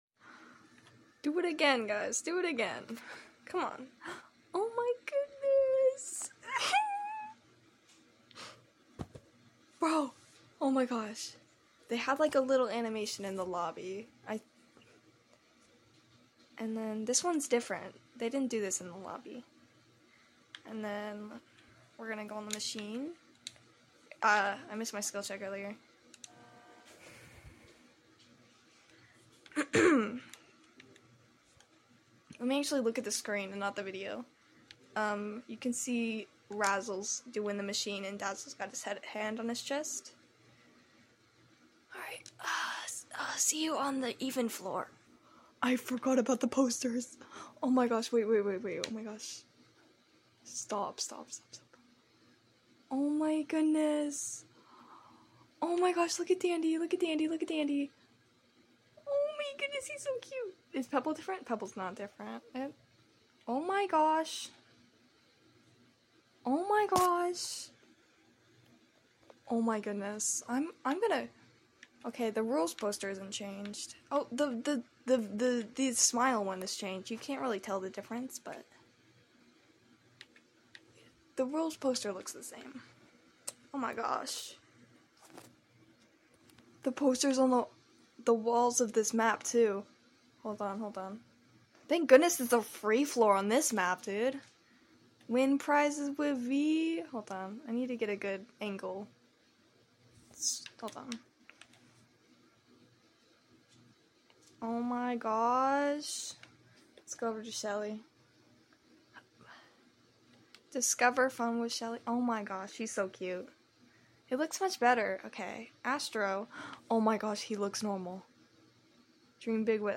sorry for bad quality